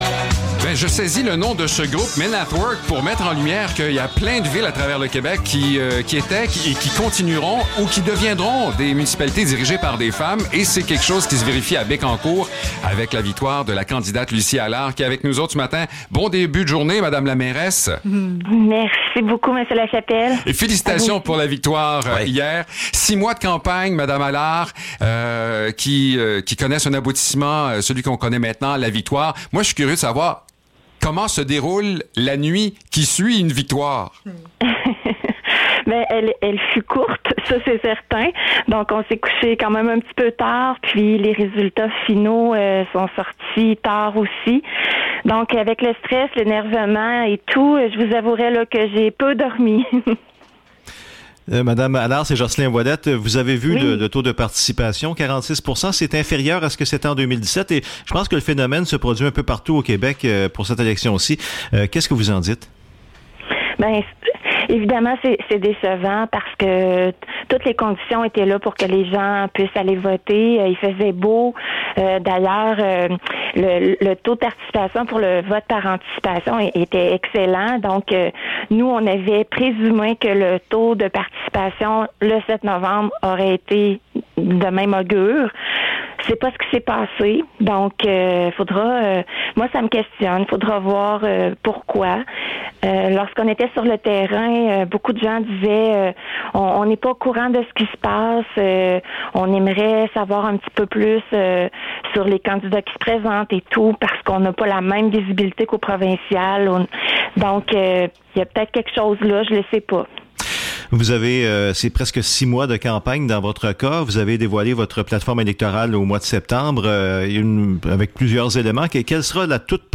Entrevue avec Lucie Allard, nouvelle mairesse de Bécancour (8 novembre 2021)